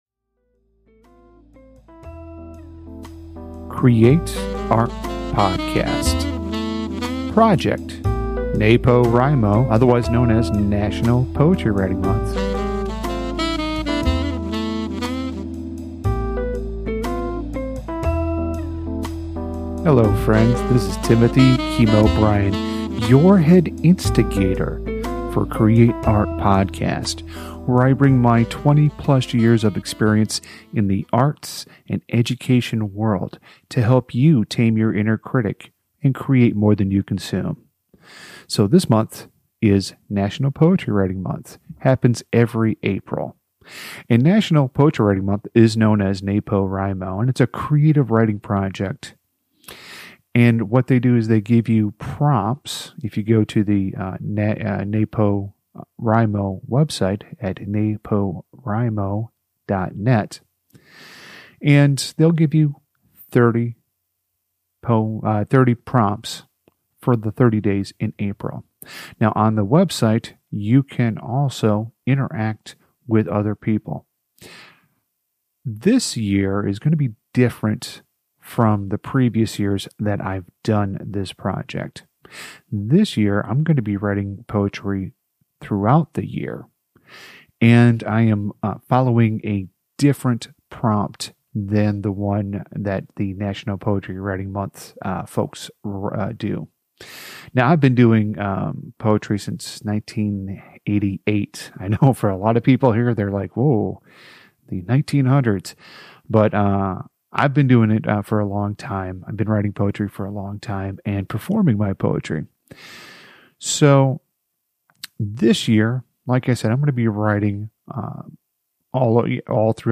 Some of the podcast episodes you will hear will be a live recording of me reading the poem to a live audience, other times I will be reading it in the comfort of my home studio.